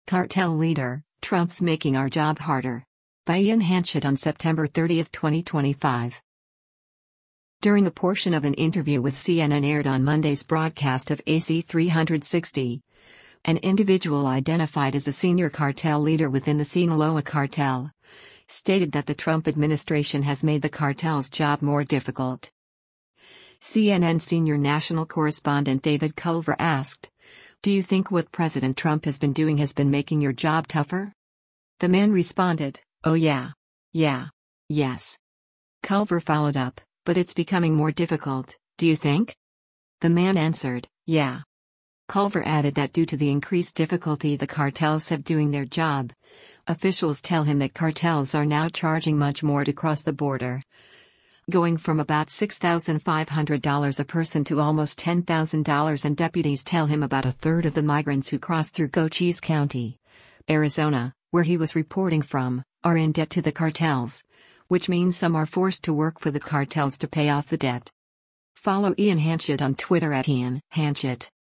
During a portion of an interview with CNN aired on Monday’s broadcast of “AC360,” an individual identified as a “senior cartel leader” within the Sinaloa Cartel, stated that the Trump administration has made the cartels’ job more difficult.